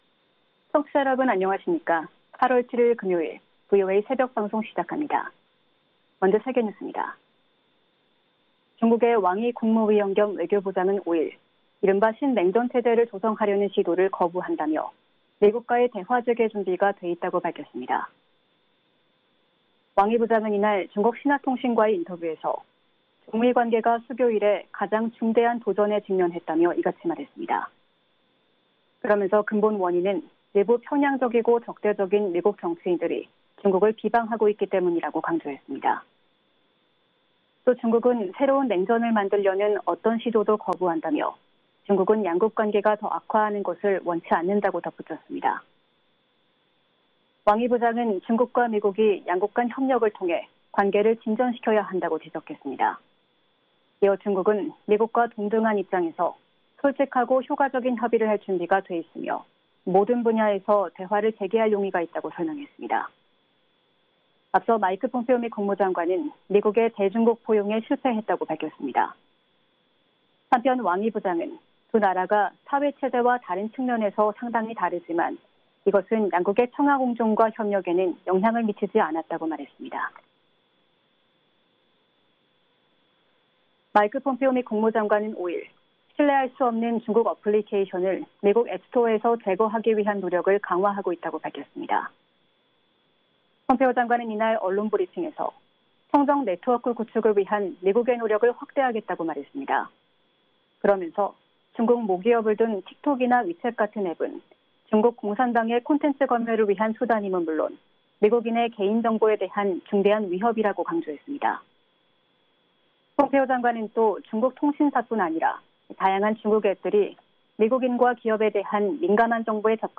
VOA 한국어 '출발 뉴스 쇼', 2020년 8월 7일 방송입니다. 도널드 트럼프 대통령이 지금 미국이 대선을 앞두지 않았다면 북한이 합의를 희망하며 협상장에 있을 것이라고 말했습니다. 북한의 지속적인 핵 개발은 지역 내 확산 위험을 초래할 수 있다고 일본 주재 미국 대사 지명자가 밝혔습니다. 미국의 국방장관이 중국과 러시아에 초점을 맞춘 안보 정책 전환의 중요성을 강조하면서 미군의 재배치를 시사했습니다.